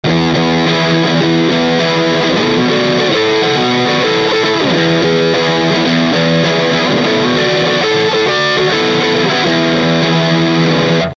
Guitar Fender STRTOCASTER
Amplifier VOX AD30VT AC30TB
次は「STYLE」をアリキックにセットした音です。
ブーミー過ぎますがＬｏの出ないアンプですと、